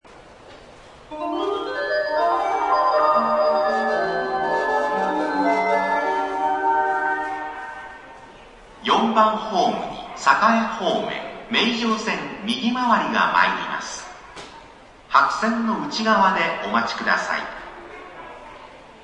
名城線左回り系統と名港線名古屋港方面行き（下り）ホームは女声、名港線金山方面行きと名城線右回り系統（上り）ホームは男声の接近放送が流れます。
冒頭には、路線・方面ごとに異なるメロディサイン（接近メロディ）が流れます。
４番ホーム接近放送（右回り）